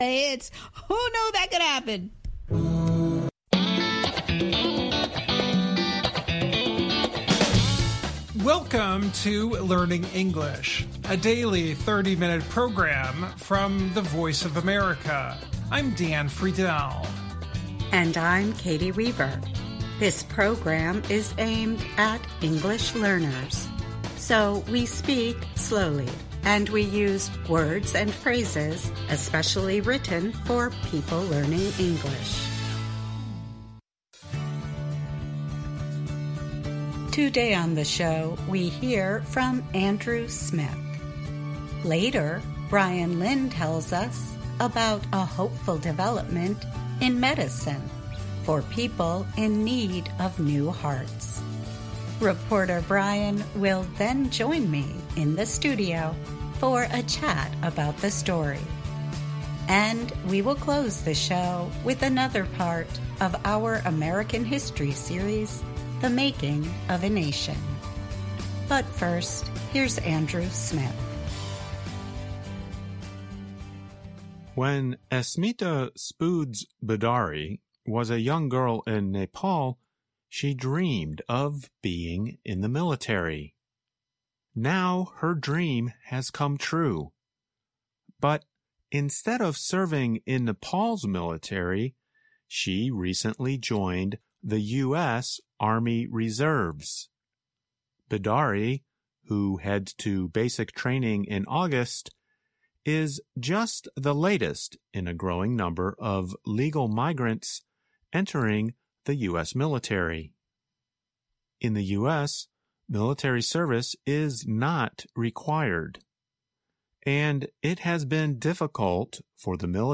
Learning English uses a limited vocabulary and are read at a slower pace than VOA's other English broadcasts.